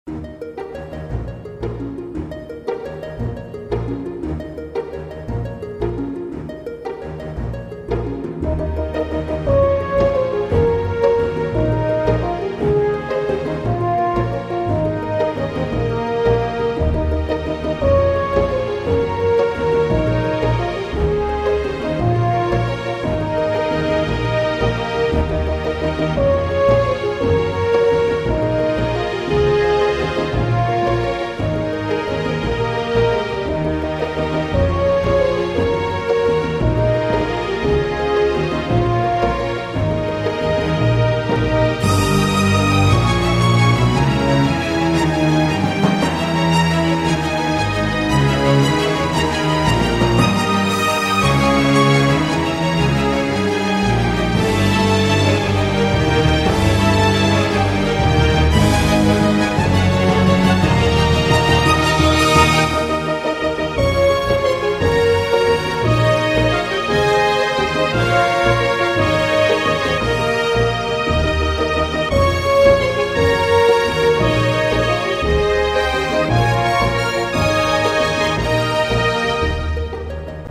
спокойные
без слов
инструментальные
symphonic metal
Симфонический метал